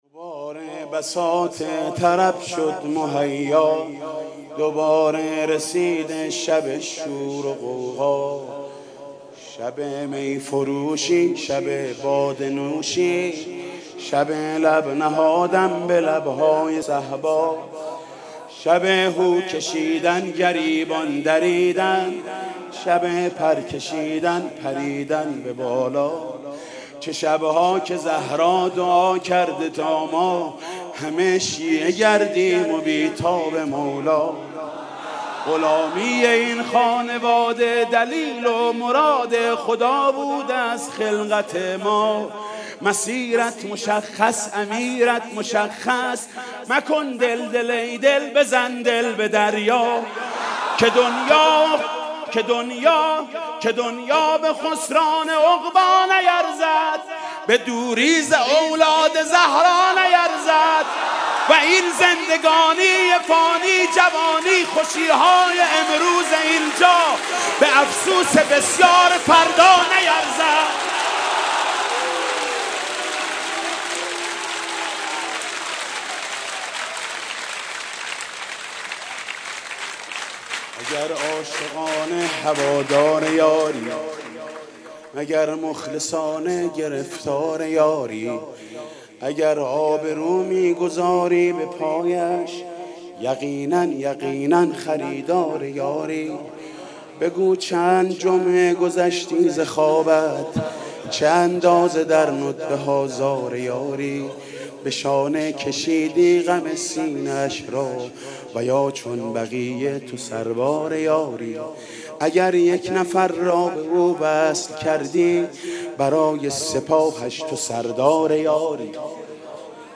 مدح امام زمان (عج)